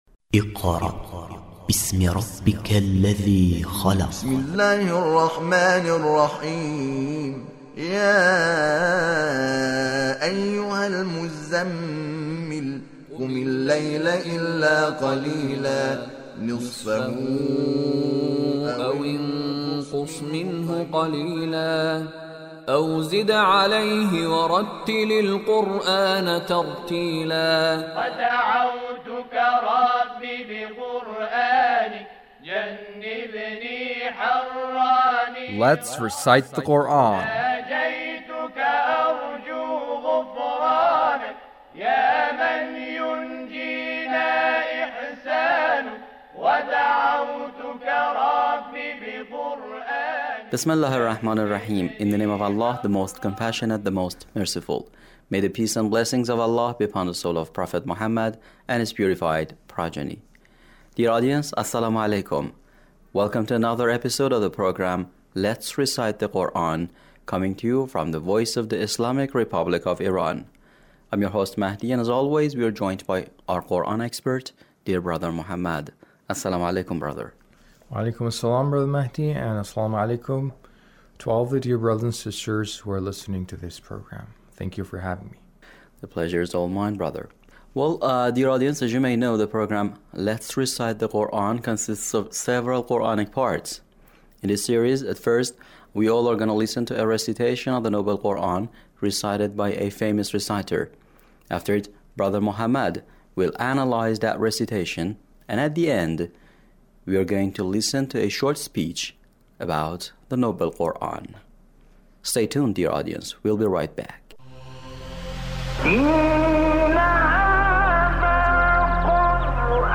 Recitation